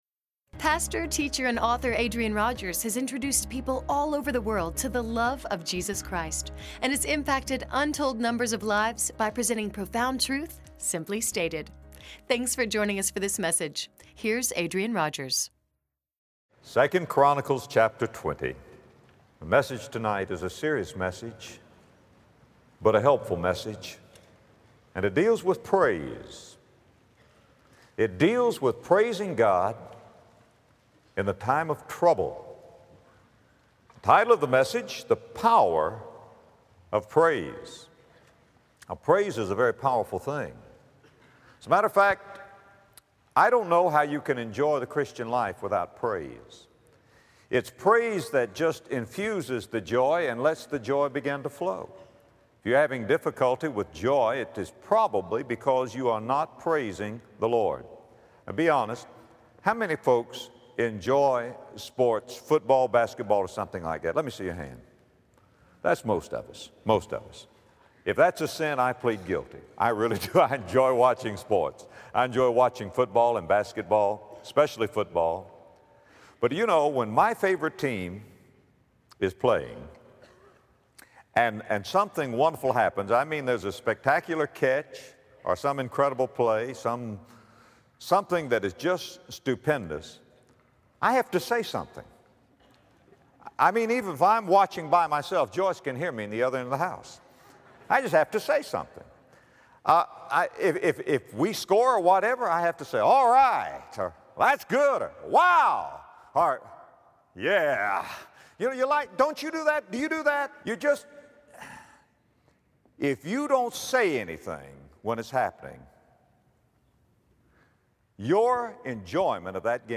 Sermon Overview Scripture Passage: 2 Chronicles 20:1-30 Praise is a powerful thing, in times of joy and in times of help, but especially in times of trouble.